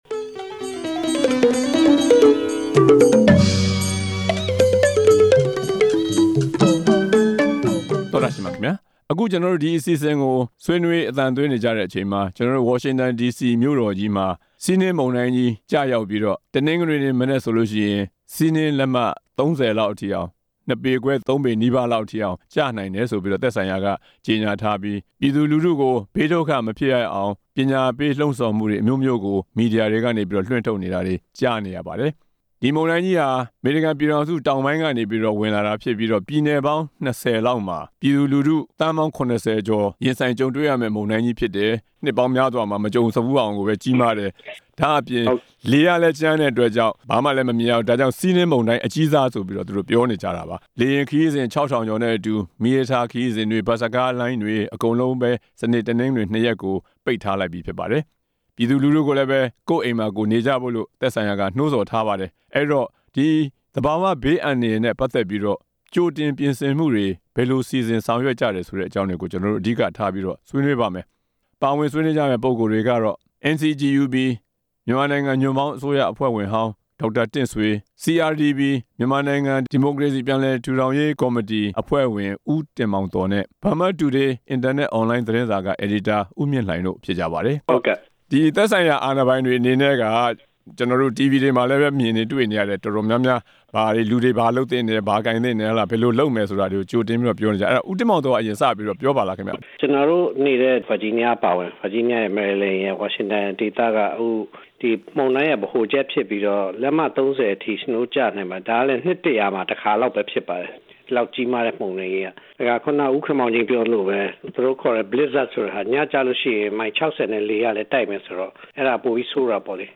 သဘာဝဘေးအန္တရာယ်ဆိုးတွေကို ကြိုတင်ကာကွယ် ရန် ဆွေးနွေးချက်